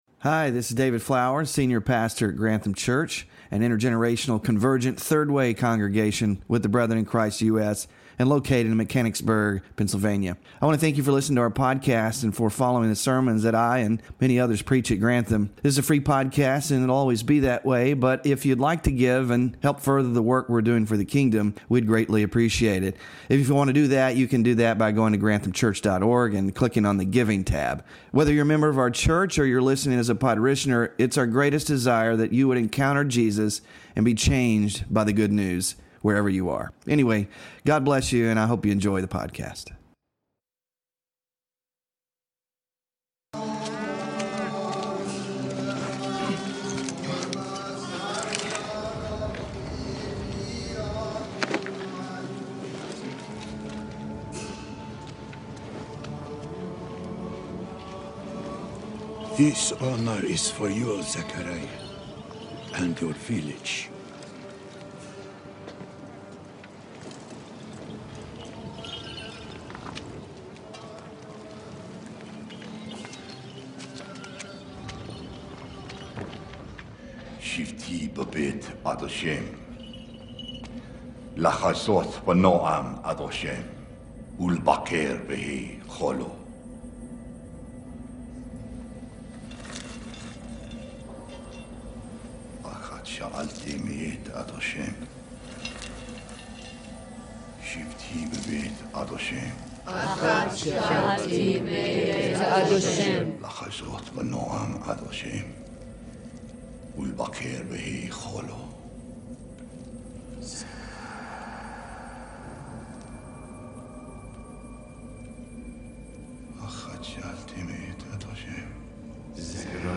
Sermon Focus: The story of John’s birth reveals a God who works through the barren and broken to show his grace.